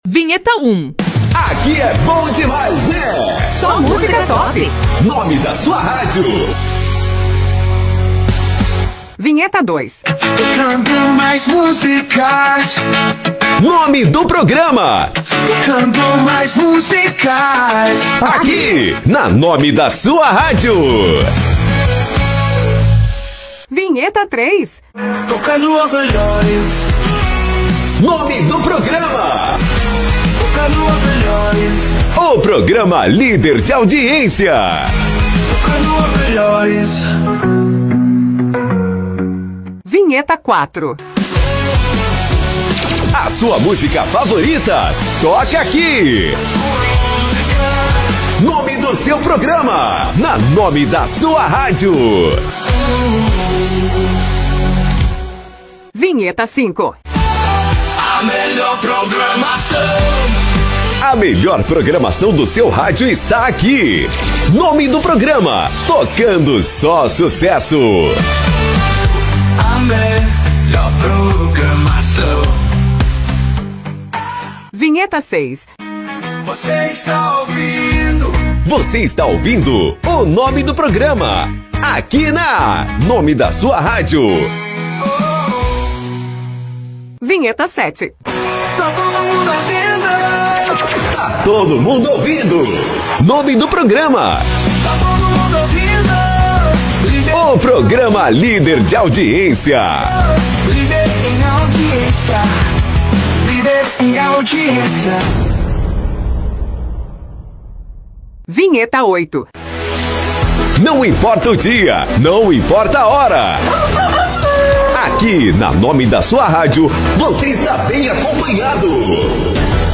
10 VHT DE RÁDIO PRÉ-MONTADAS
– Estilos Jovem/  Pop Rock